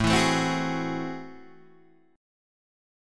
A Minor Major 13th